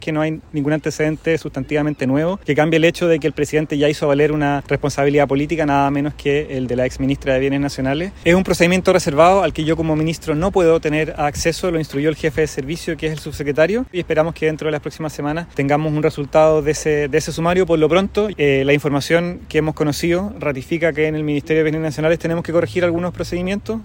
Al respecto, el ministro de Bienes Nacionales, Francisco Figueroa, defendió la labor del Gobierno planteando que ya se han hecho valer las responsabilidades políticas.